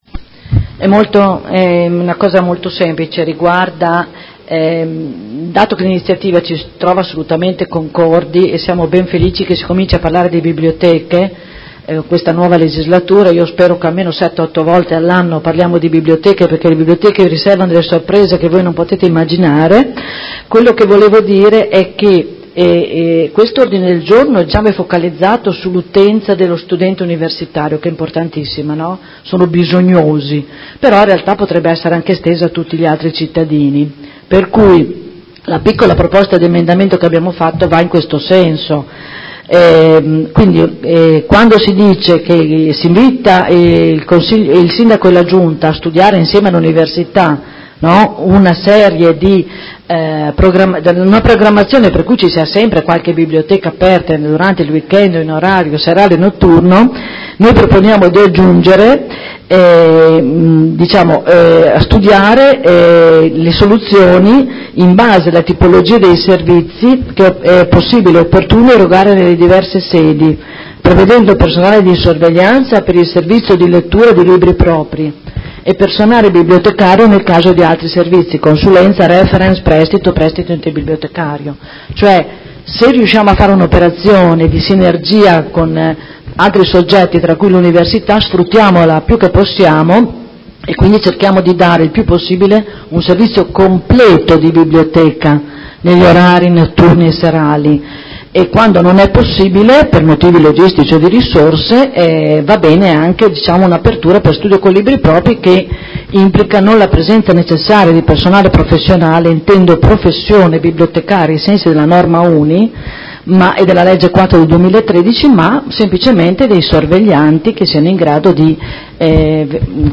Enrica Manenti — Sito Audio Consiglio Comunale